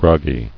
[grog·gy]